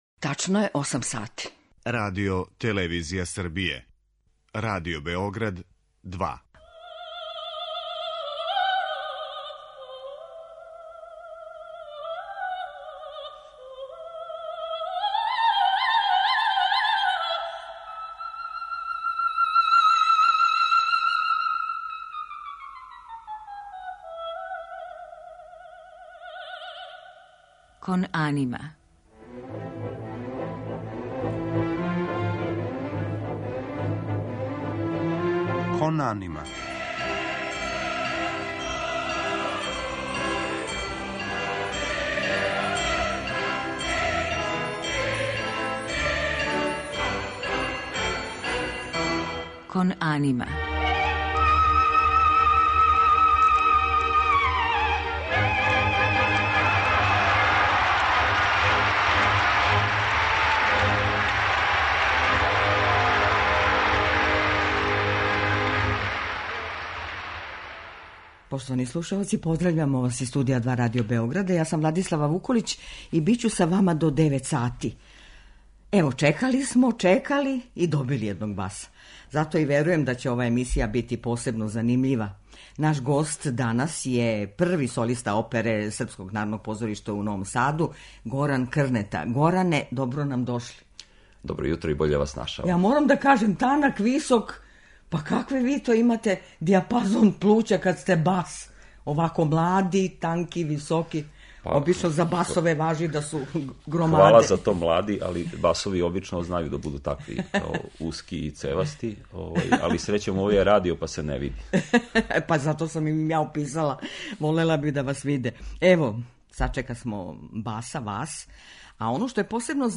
У музичком делу биће емитоване арије из опера Волфганга Амадеуса Моцарта, Ђузепа Вердија, Петра Иљича Чајковског, Ђоакина Росинија, као и Хајдновог ораторијума Стварање света.